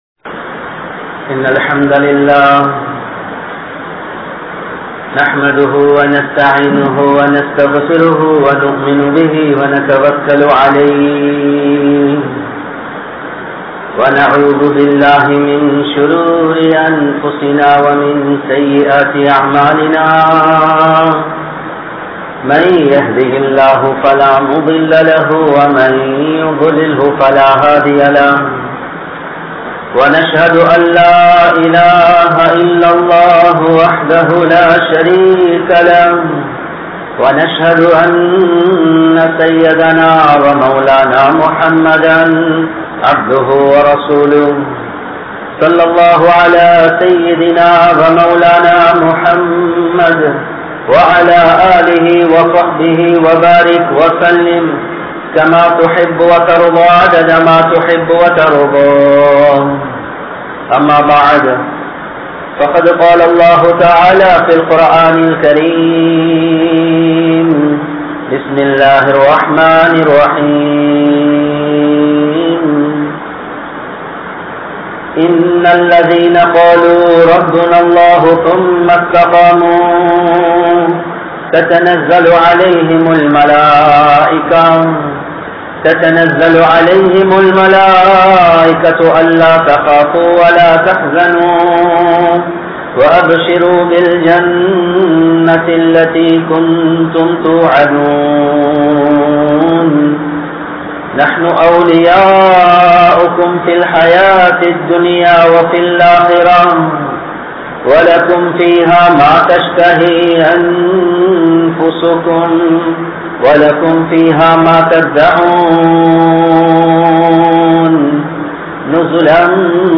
Muslim Samoohathai Kaatti Kodukatheerhal(முஸ்லிம் சமூகத்தைக் காட்டிக் கொடுக்காதீர்கள்) | Audio Bayans | All Ceylon Muslim Youth Community | Addalaichenai